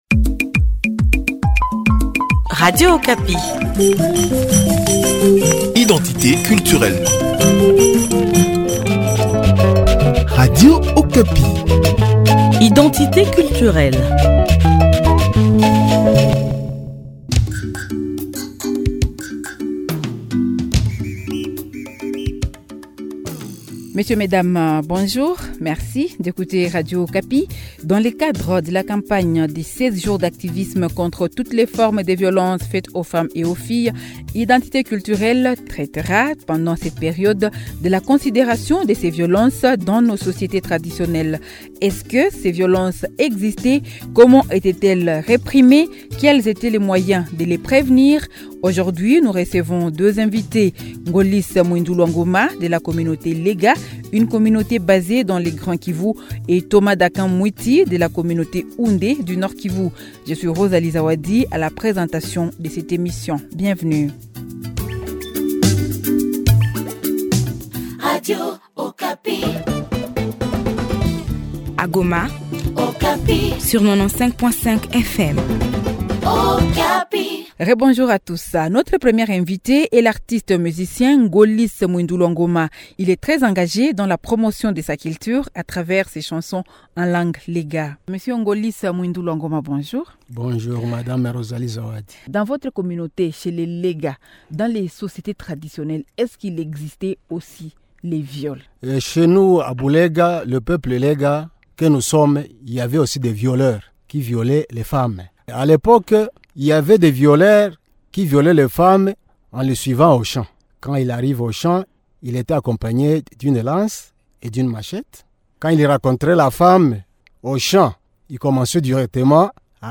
Pour en discuter, nous accueillons aujourd'hui deux invités